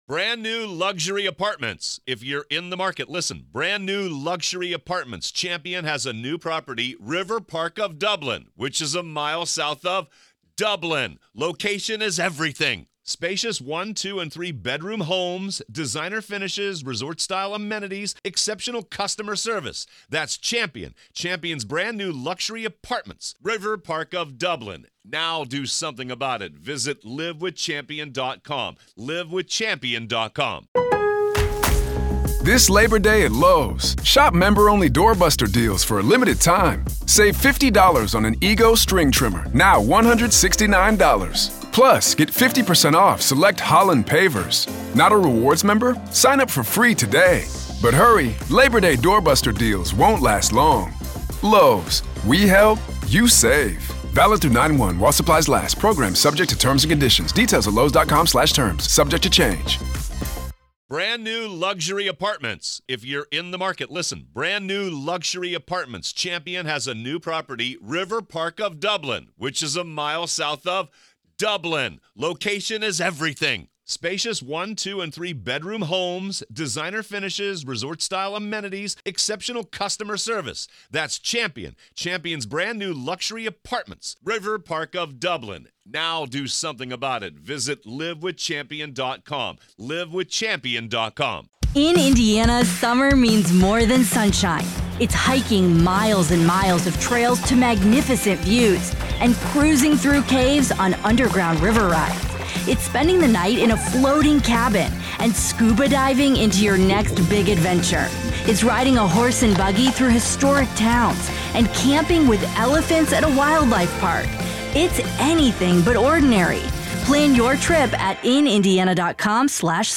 LLC joins us today in a three-part interview to discuss the mounting evidence against Bryan Kohberger and where the case may be headed.